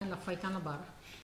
Langue Maraîchin
Locution